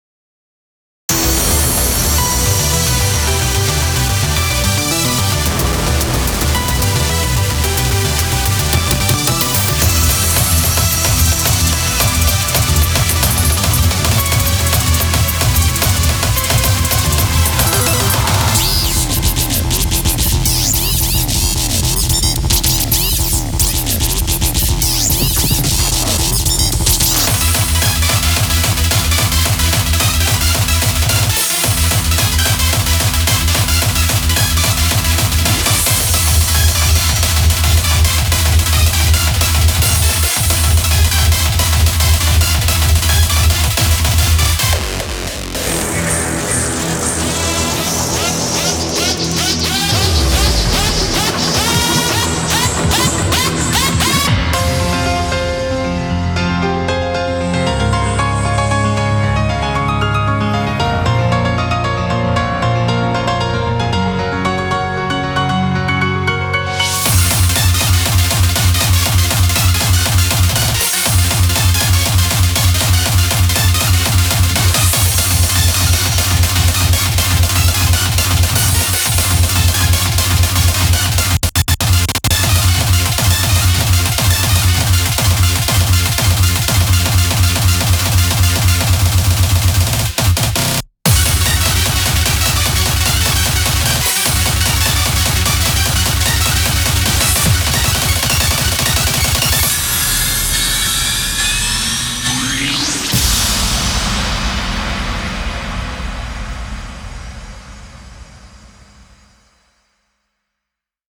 BPM110-428